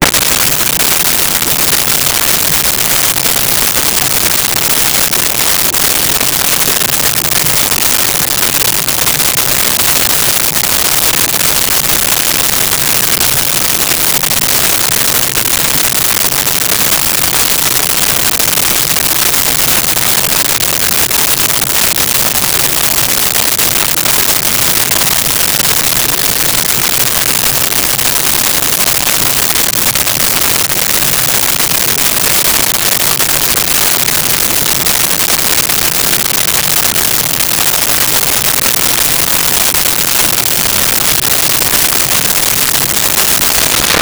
Water Boil
Water Boil.wav